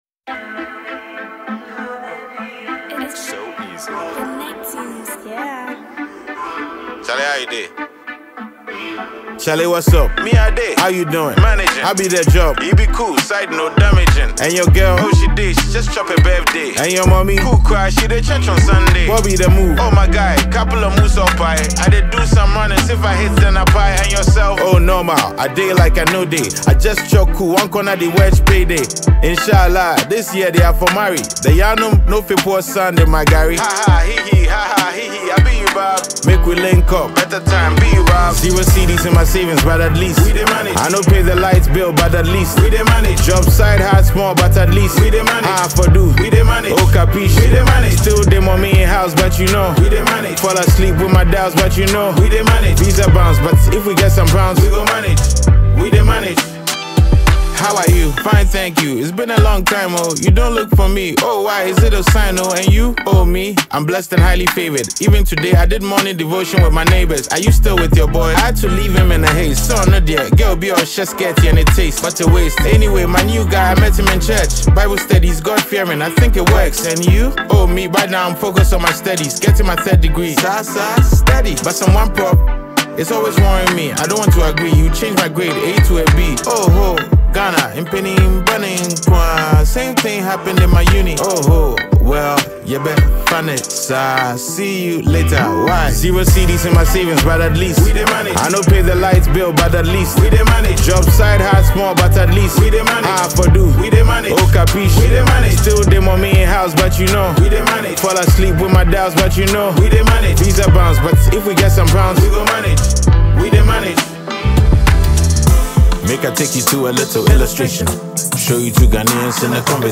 Ghanaian rap star